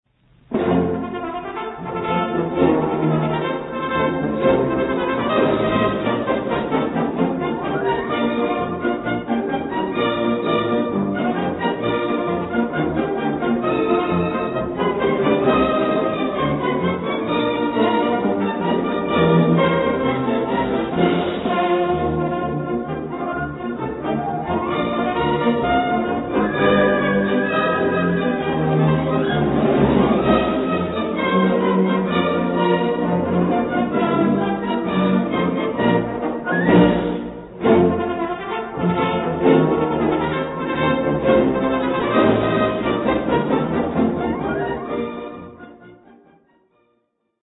авиационный марш ВВС РККА